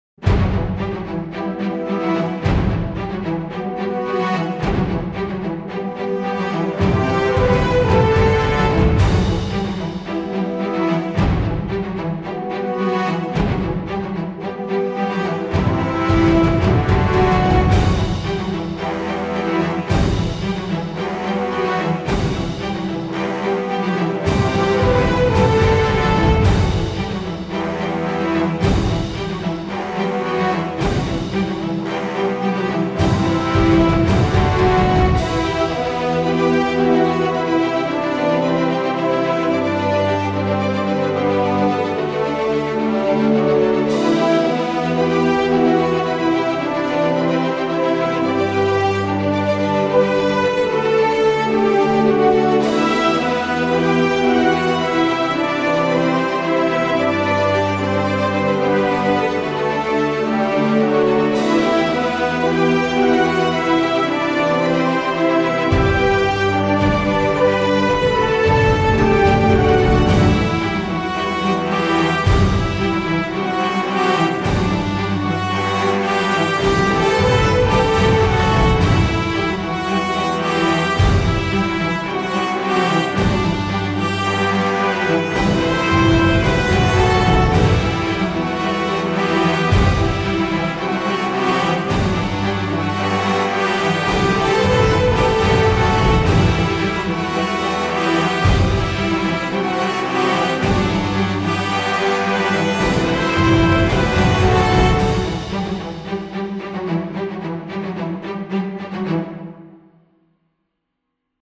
映画のトレーラーのような迫力あるかんじ。